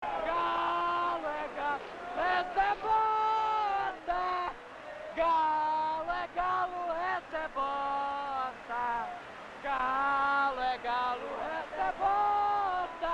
galo e galo o resto e bosta Meme Sound Effect